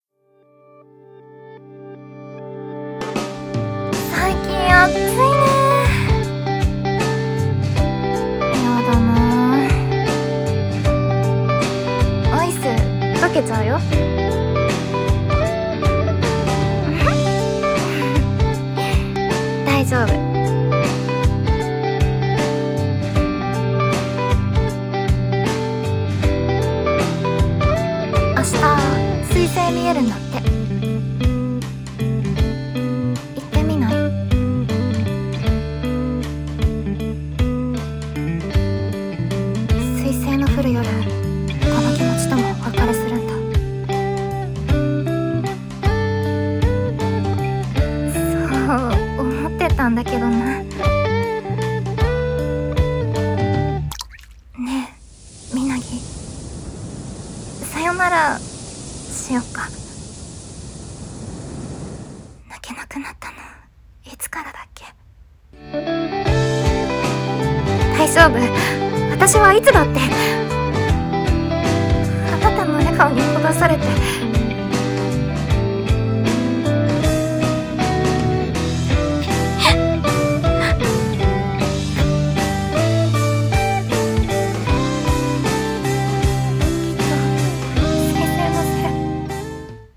【二人声劇】彗星乙夜のアンダンテ